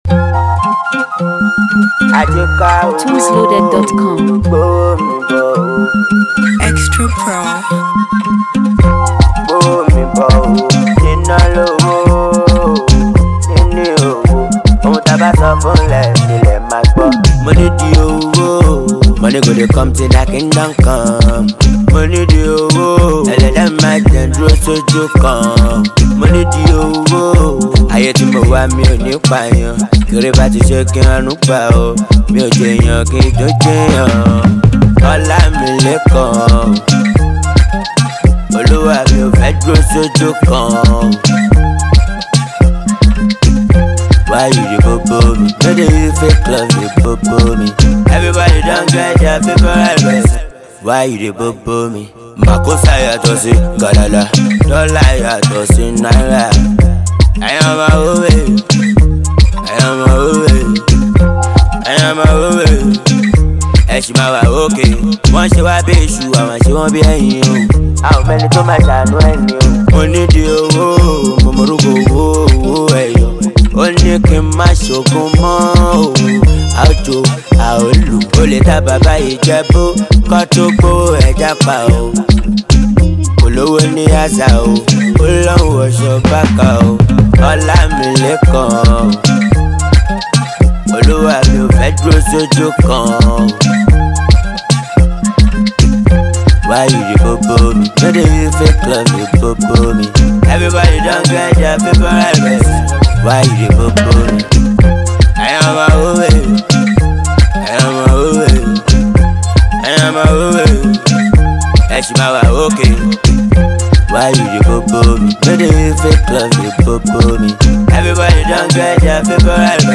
Street-hop
gritty anthem
fuji-inspired vocals with fast-paced beats